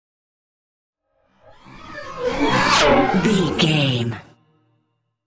Sci fi vehicle pass by super fast
Sound Effects
futuristic
intense
pass by